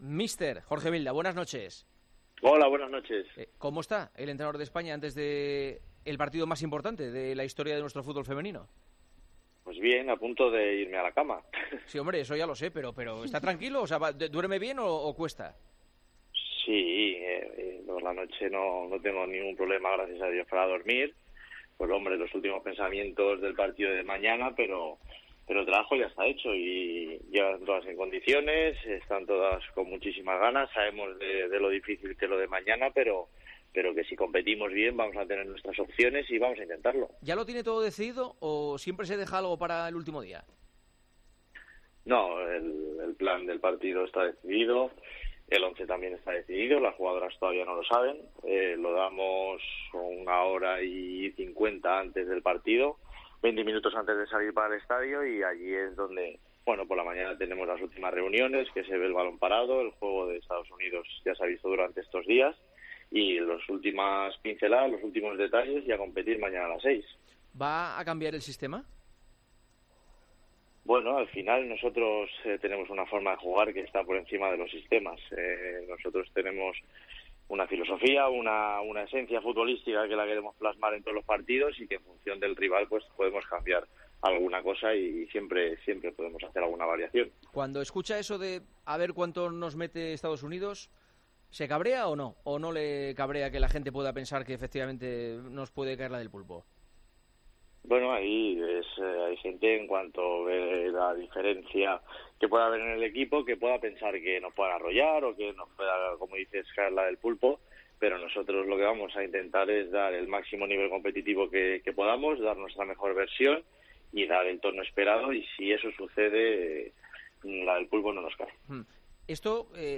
Hablamos con el seleccionador nacional en la previa del encuentro de octavos del Mundial ante EEUU que a pesar de todo tiene confianza en hacer buen papel.